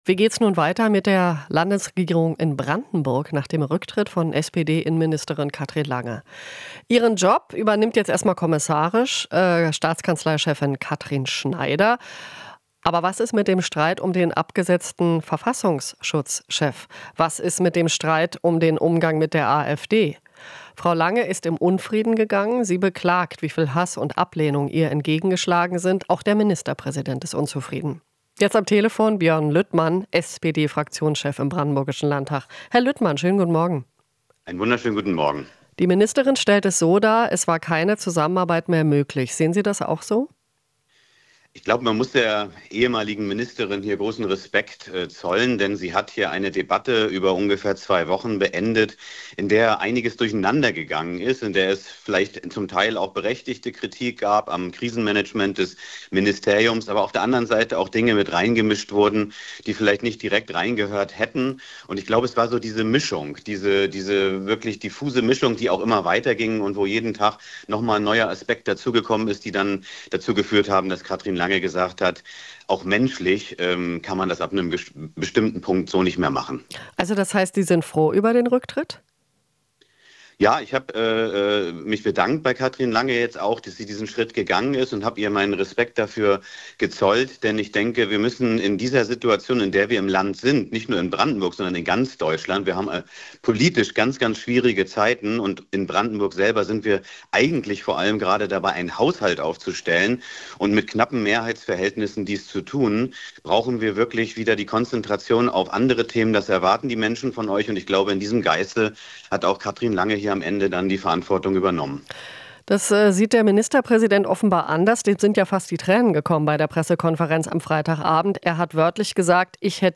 Björn Lüttmann ist SPD-Fraktionschef im brandenburgischen Landtag.